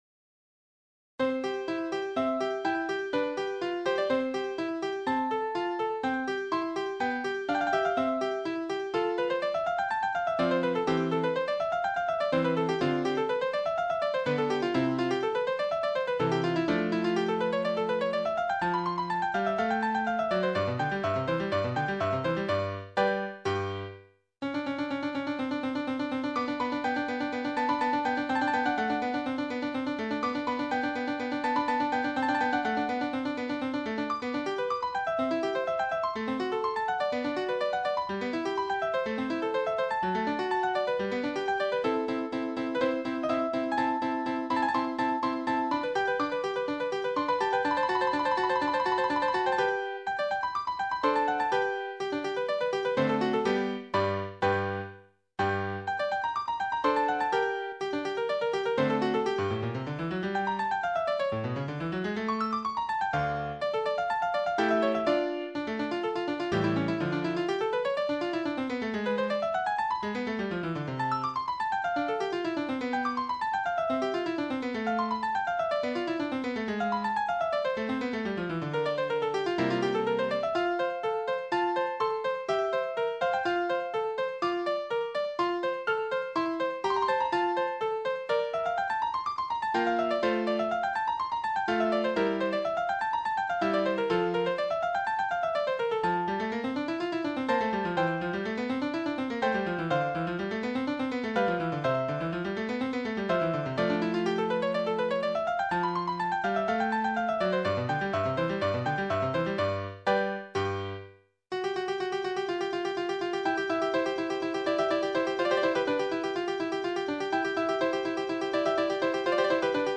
デジタルはデジタルらしく抑揚もつけずシンプルに入力。